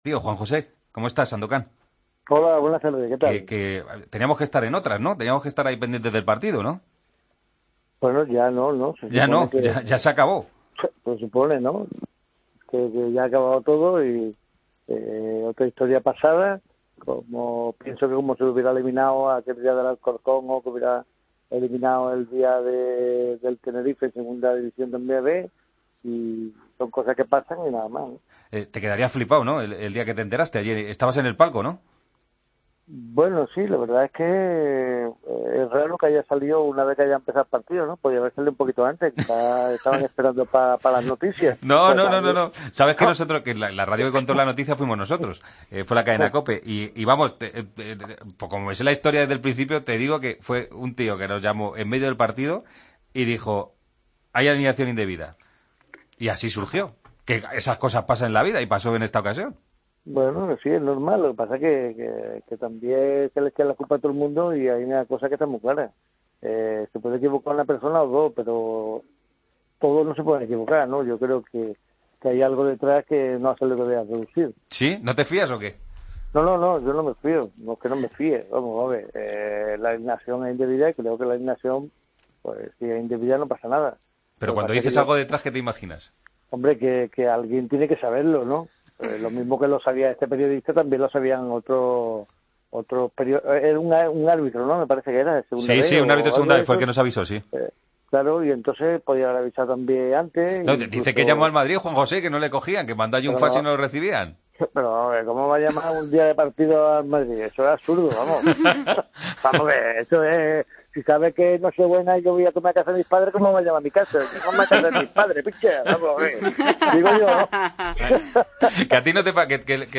Hablamos con el exjugador del Real Madrid y el Cádiz del partido que ambos equipos deberían haber jugado.